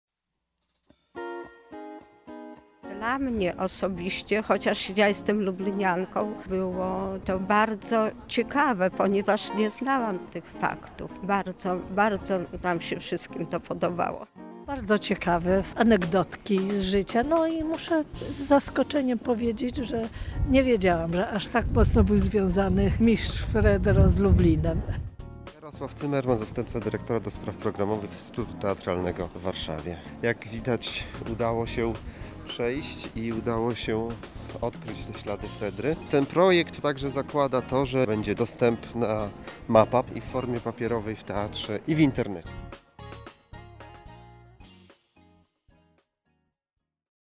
Na miejscu był również nasz reporter.